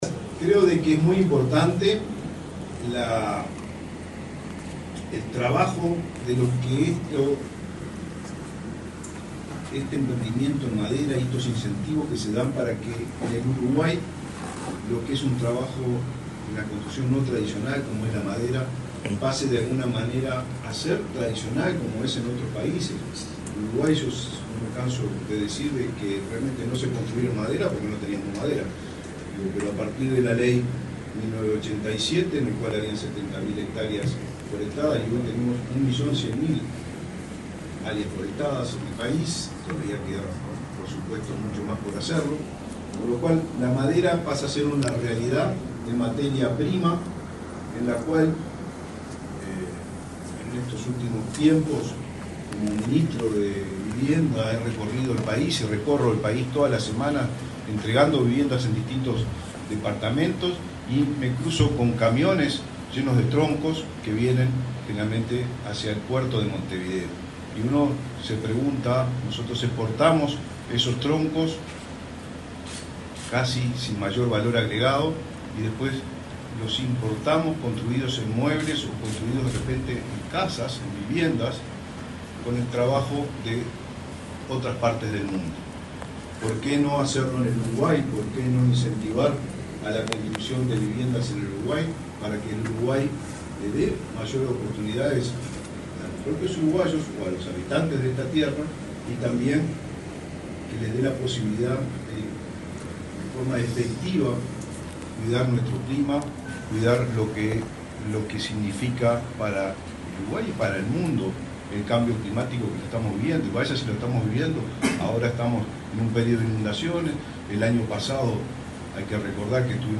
Palabras del ministro de Vivienda, Raúl Lozano